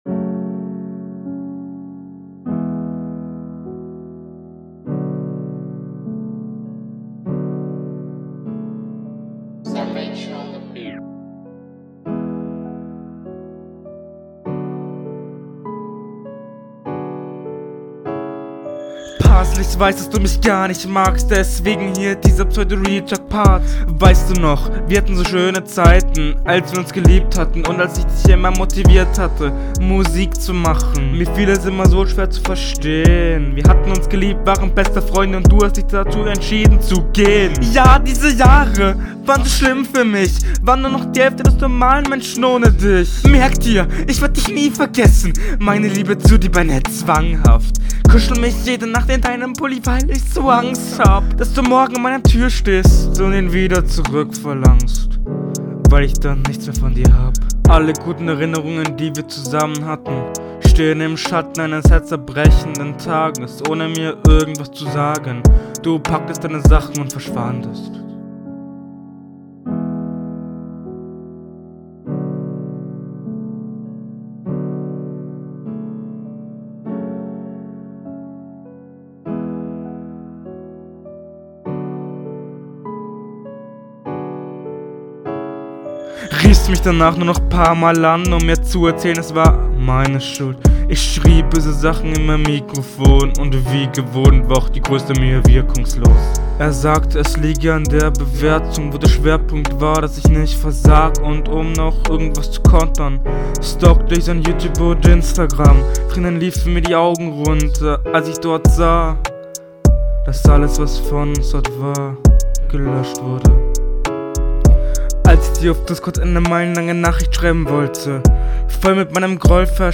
Finde den Ansatz nicht schlecht aber leider ist die Stimme sehr überteuert, der Text ja …
Ok, ein Piano Beat.